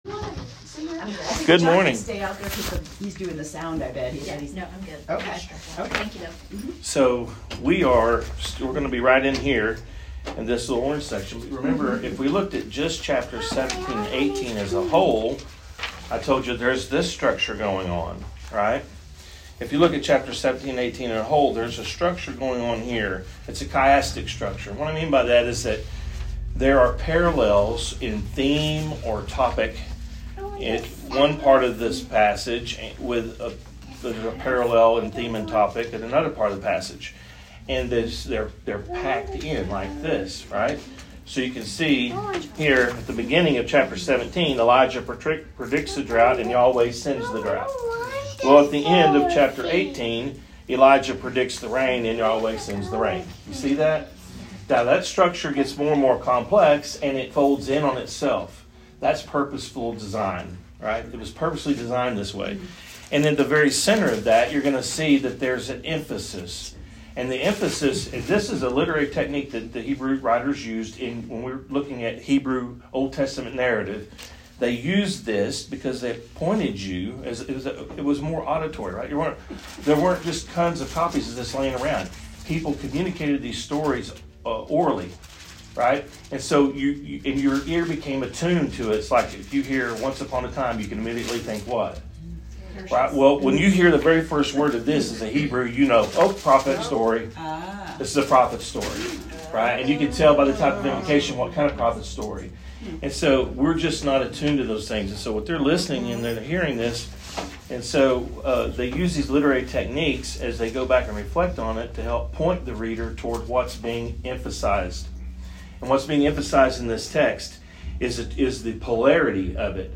1 Kings 17:8-24 3Rivers Presbyterian Church - Sermons podcast